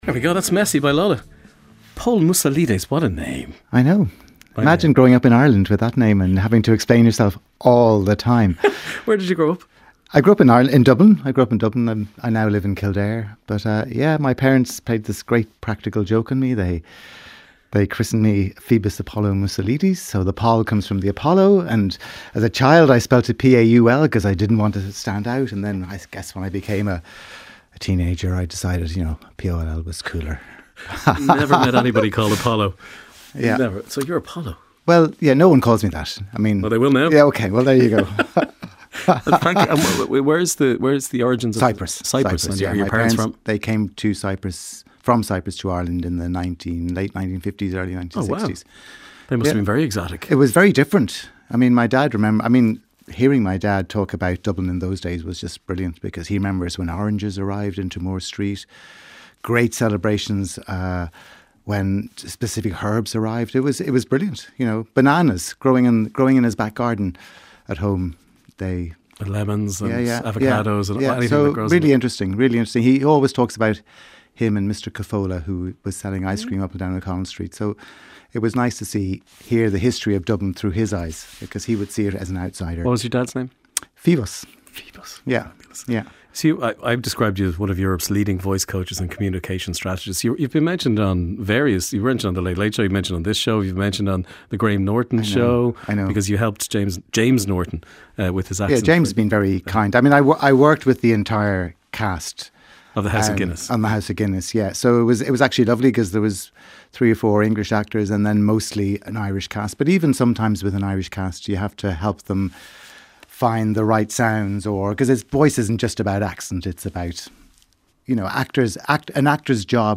Highlights from the daily radio show with Ray D'Arcy. Featuring listeners' stories and interviews with authors, musicians, comedians and celebrities.